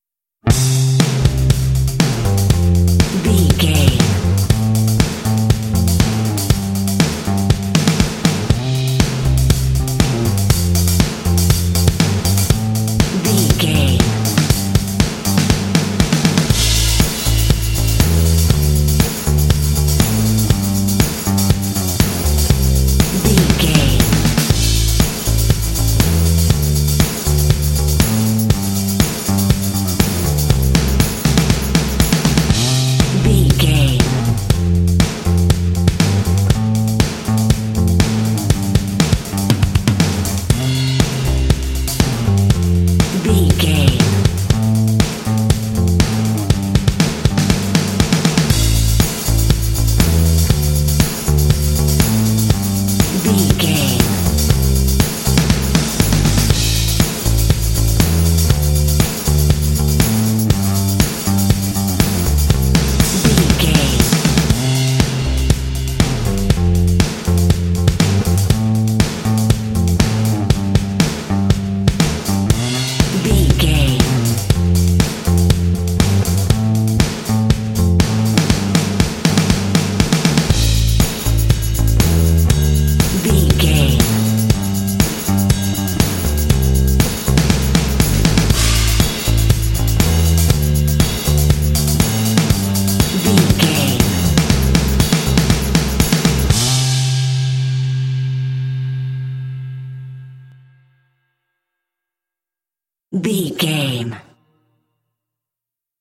Uplifting
Ionian/Major
driving
bouncy
happy
drums
bass guitar
80s
rock
pop
alternative rock
indie